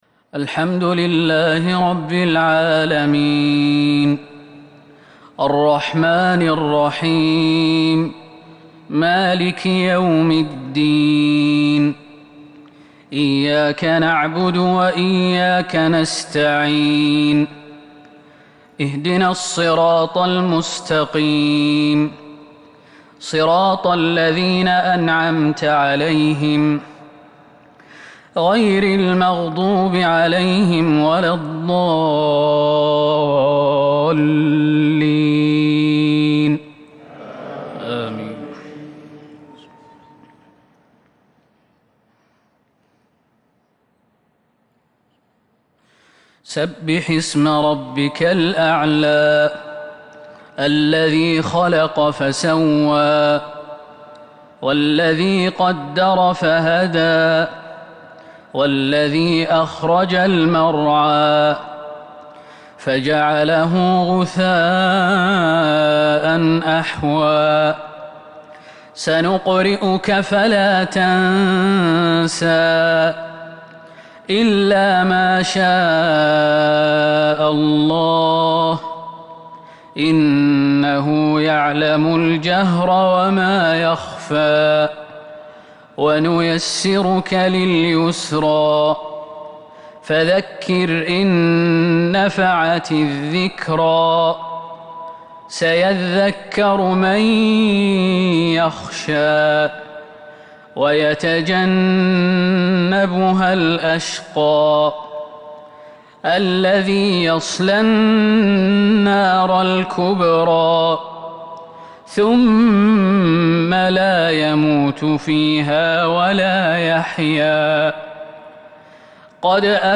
صلاة العشاء من سورتي الأعلى والضحى ٢٠/١/١٤٤٢ isha prayer from surah Al-A’la and Ad-Duha 8/9/2020 > 1442 🕌 > الفروض - تلاوات الحرمين